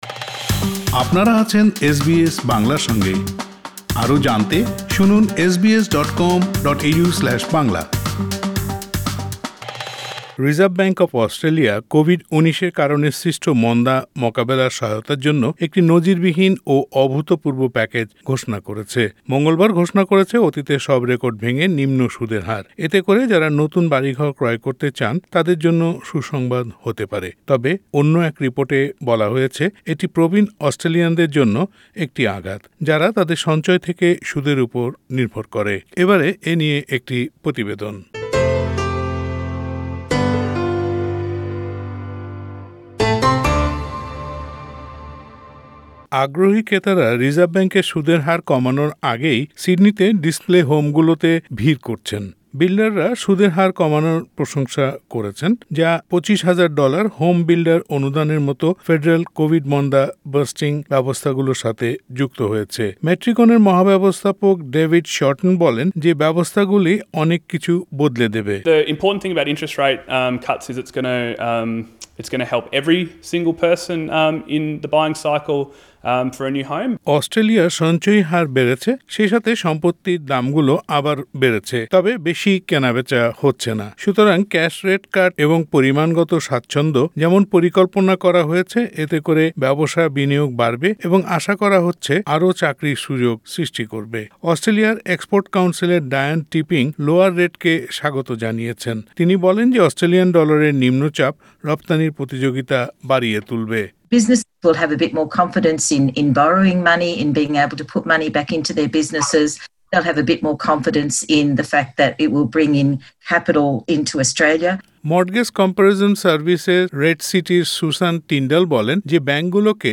রিসার্ভ ব্যাঙ্ক অফ অস্ট্রেলিয়া কোভিড-১৯ এর কারণে সৃষ্ট মন্দা মোকাবেলার সহায়তার জন্য একটি নজিরবিহীন ও অভূতপূর্ব প্যাকেজ ঘোষণা করেছে।প্রতিবেদনটি শুনতে উপরের অডিও প্লেয়ারের লিংকটিতে ক্লিক করুন।